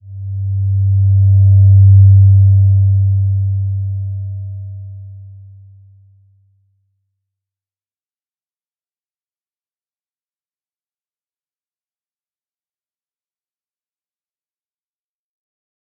Slow-Distant-Chime-G2-p.wav